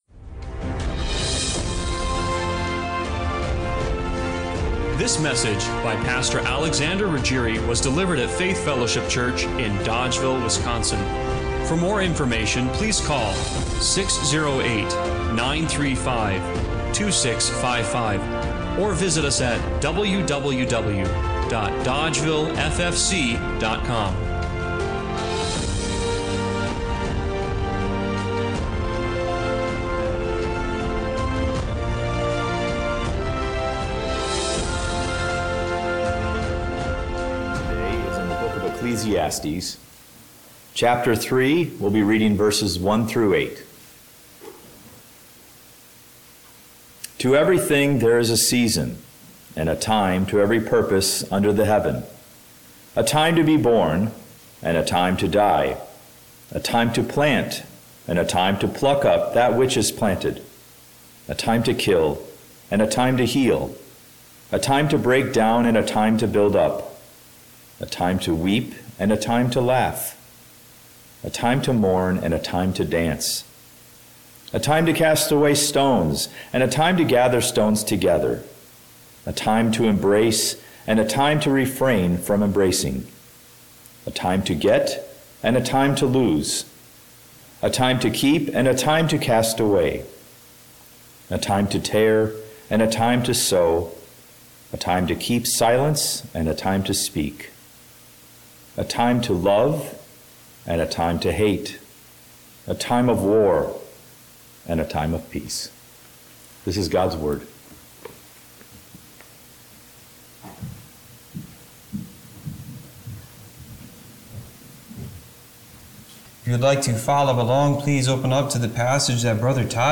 Ecclesiastes 3:1-8 Service Type: Sunday Morning Worship Have you ever felt like time was slipping through your fingers